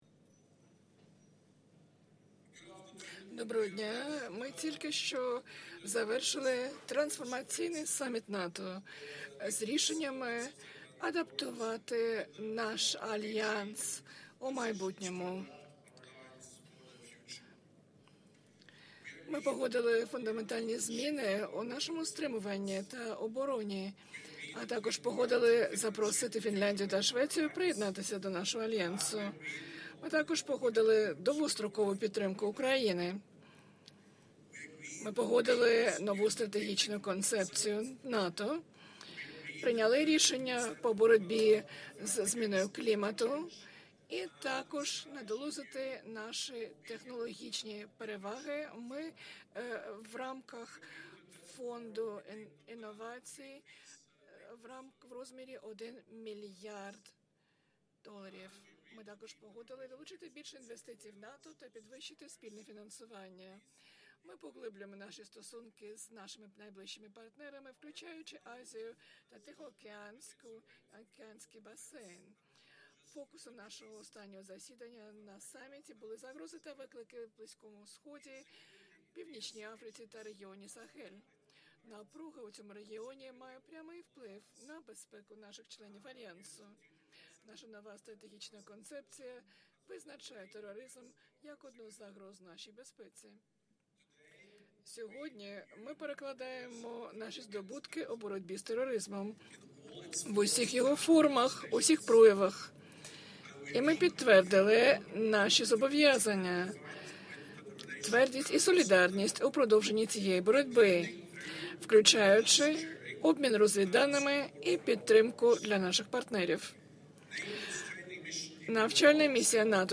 J ens S toltenberg Final Press Conference Following NATO Summit in Madrid delivered 30 June 2022, Madrid, Spain Audio mp3 of Address and Q&A English Audio mp3 of Address and Q&A Ukrainian Audio mp3 of Address and Q&A Russian Audio AR-XE mp3 of Address and Q&A English